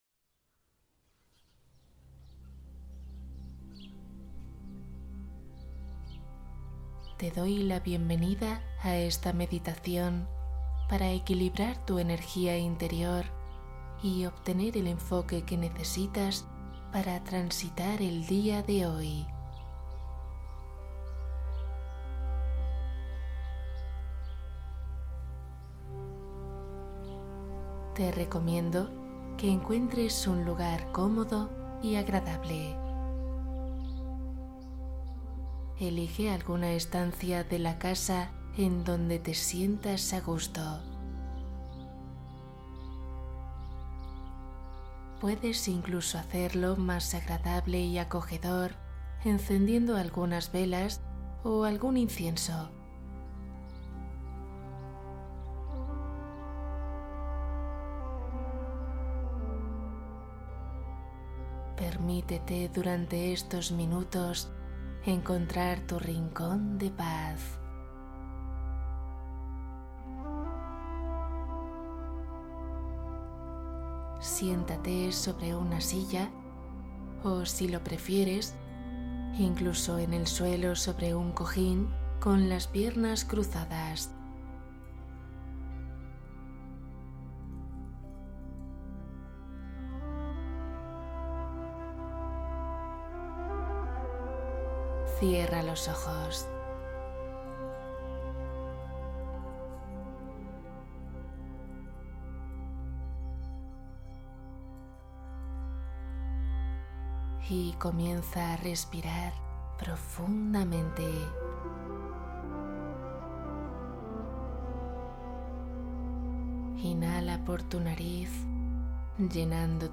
Meditación navideña Sueño profundo con cuento relajante